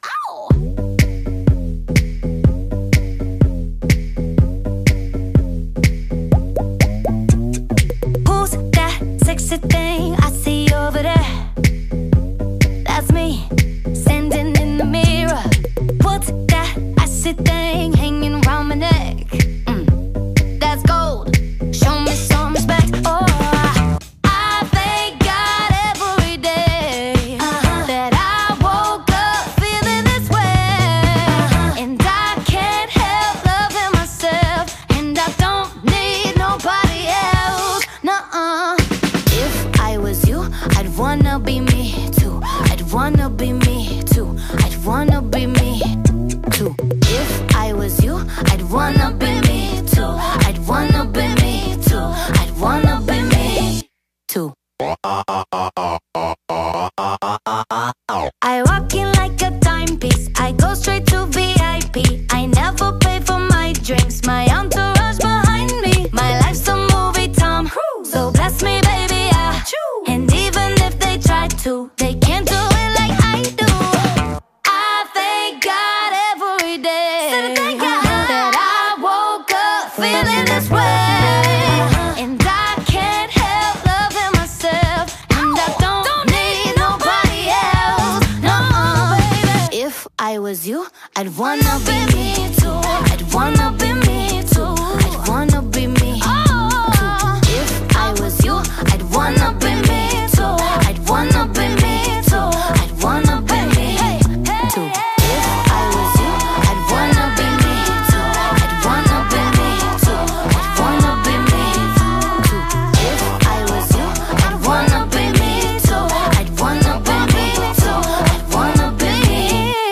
BPM124
Audio QualityMusic Cut